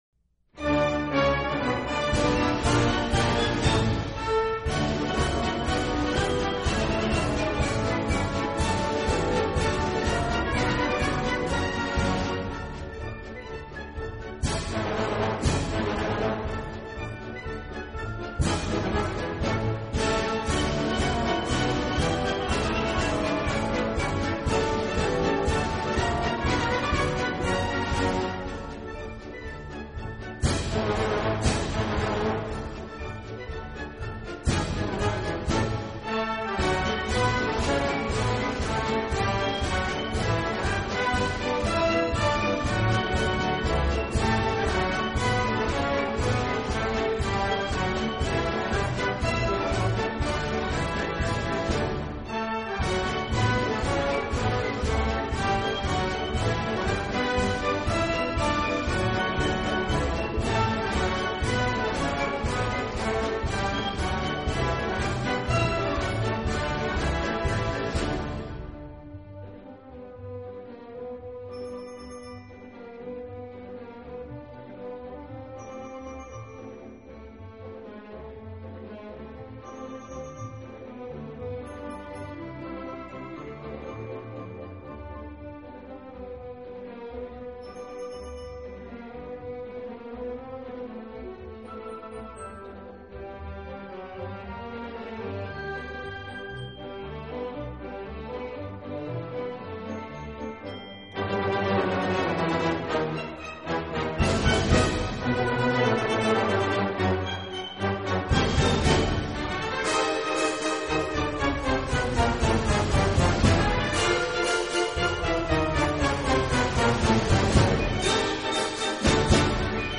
类 别：管弦乐